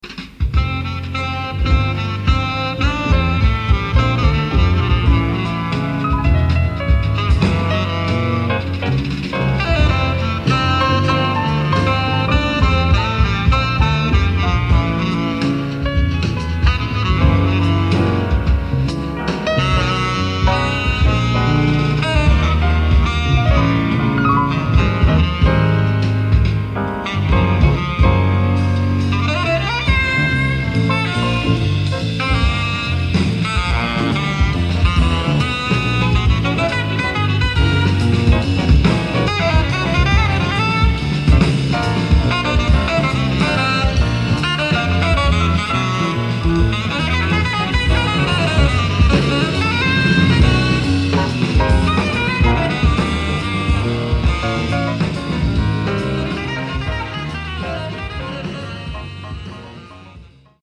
Piano, Keyboards, Percussion
Electric Bass, Percussion
Drums, Congas